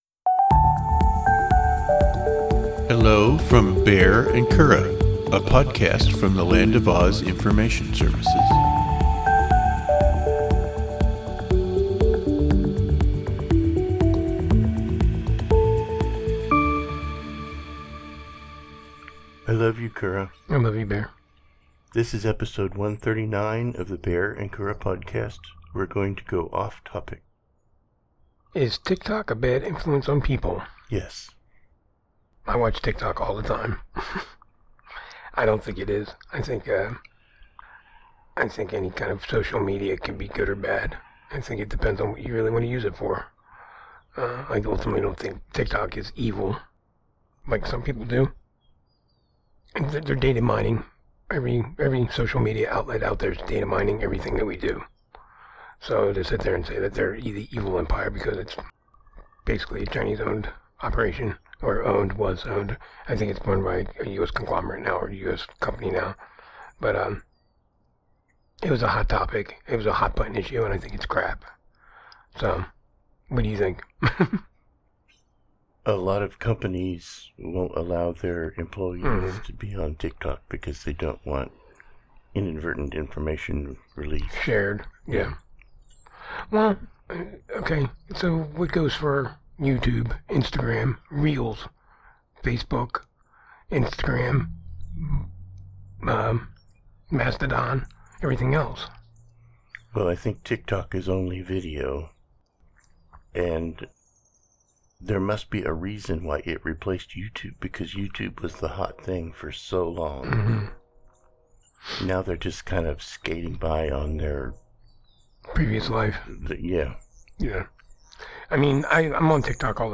Two married gay guys discuss life, synergy, and the pursuit of happiness.